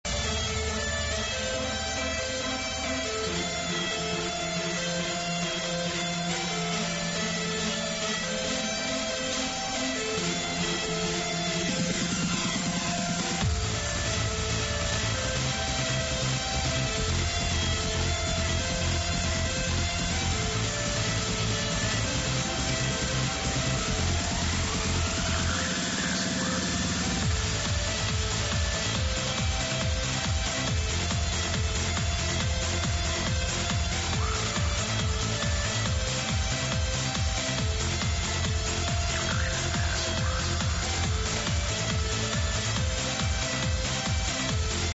the version on the mp3 sounds abit tougher